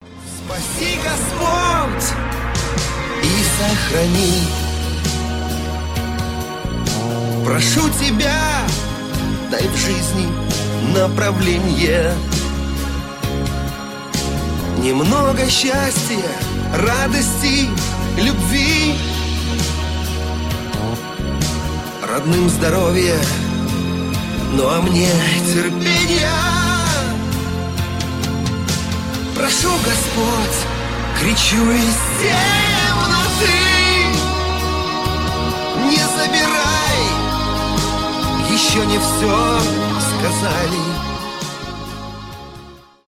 душевные , шансон